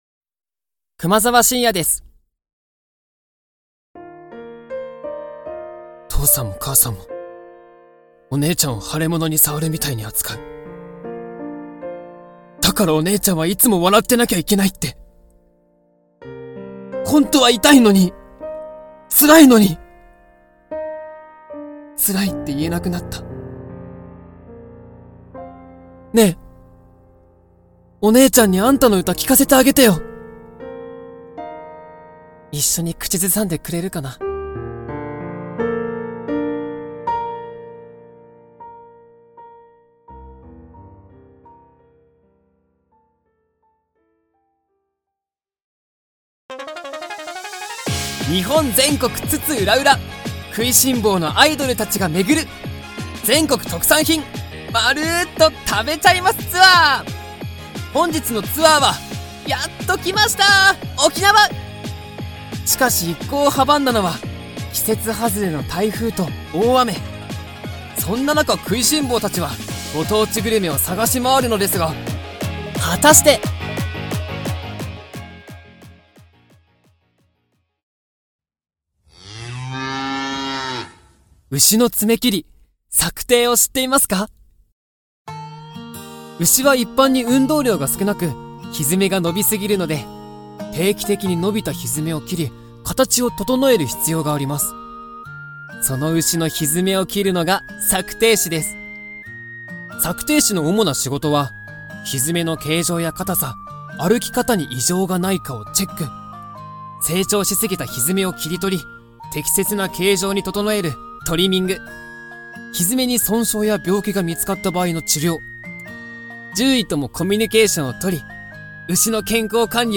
若く、甘えた感じの声